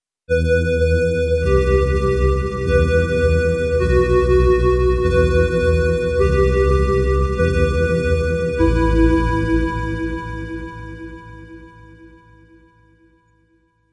宇宙飞船" 宇宙飞船隆隆声bg2
描述：用vst乐器albino制作
Tag: 未来 无人驾驶飞机 驱动器 背景 隆隆声 黑暗 冲动 效果 FX 急诊室 悬停 发动机 飞船 氛围 完善的设计 未来 空间 科幻 电子 音景 环境 噪音 能源 飞船 大气